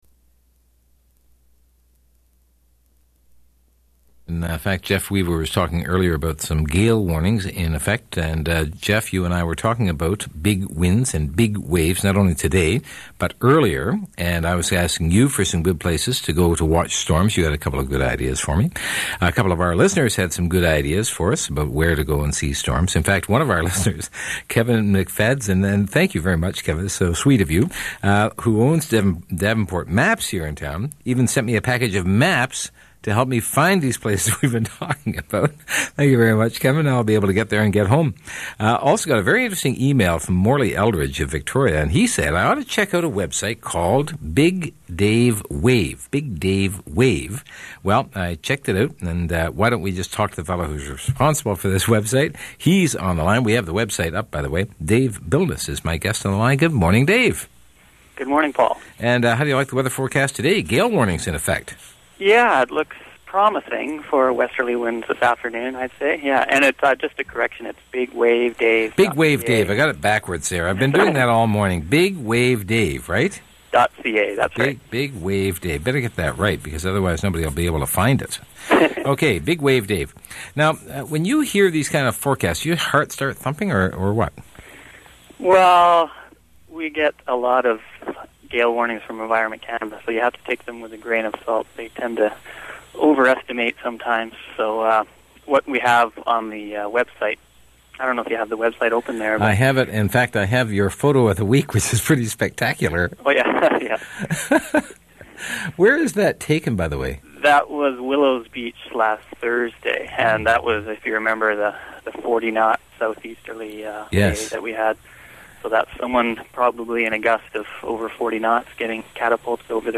Good interview...
and excellent sound quality on the web version!